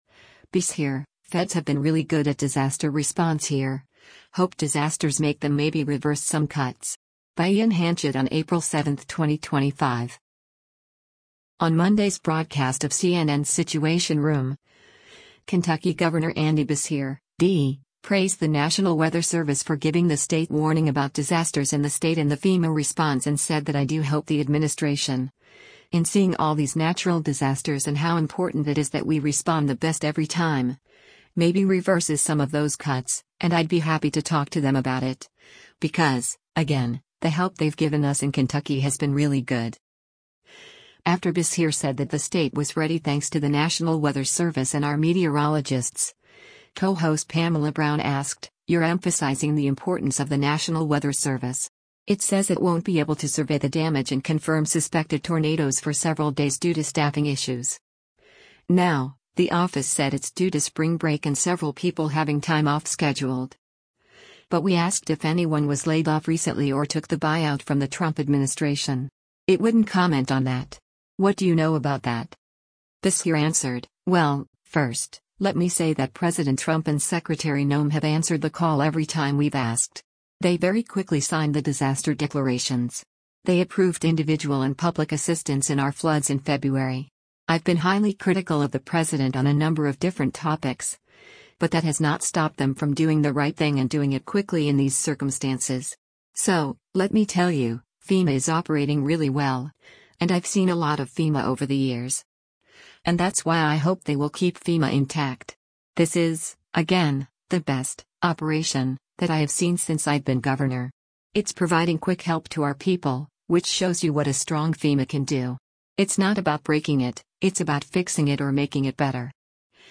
On Monday’s broadcast of CNN’s “Situation Room,” Kentucky Gov. Andy Beshear (D) praised the National Weather Service for giving the state warning about disasters in the state and the FEMA response and said that “I do hope the administration, in seeing all these natural disasters and how important it is that we respond the best every time, maybe reverses some of those cuts, and I’d be happy to talk to them about it, because, again, the help they’ve given us in Kentucky has been really good.”